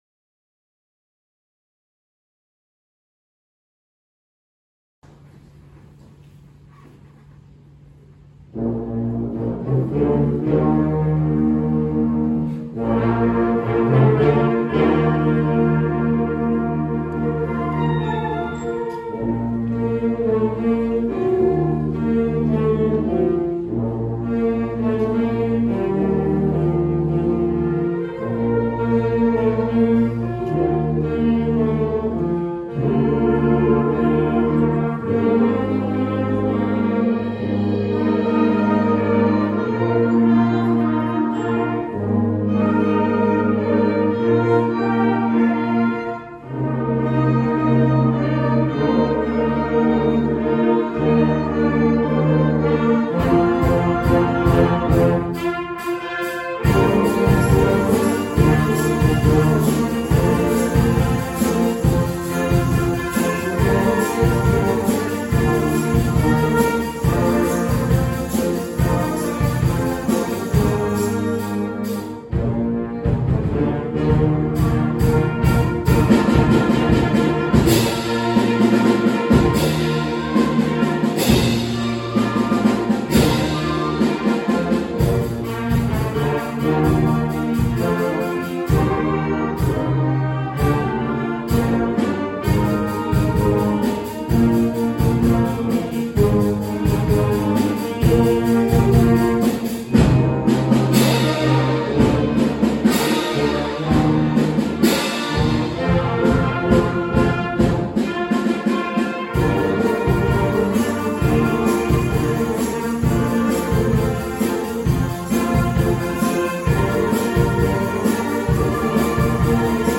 Junior Wind - November 22